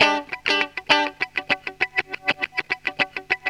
GTR 61 EM.wav